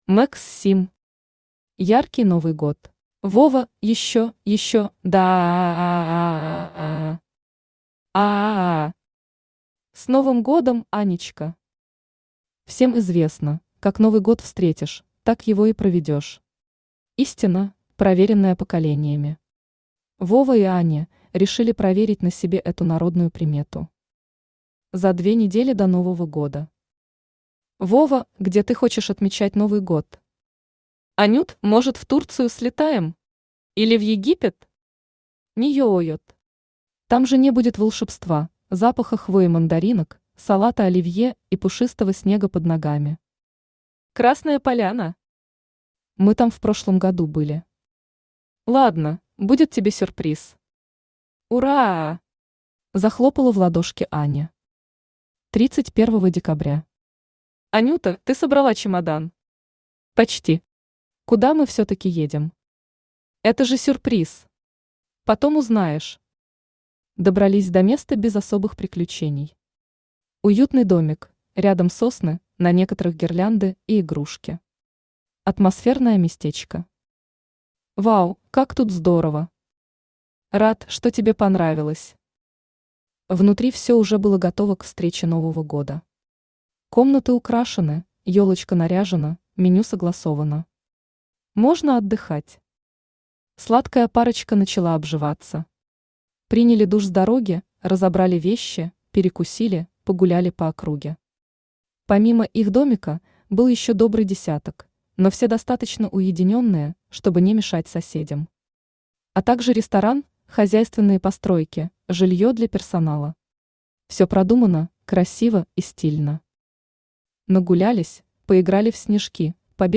Aудиокнига Яркий Новый Год Автор Max Sim Читает аудиокнигу Авточтец ЛитРес.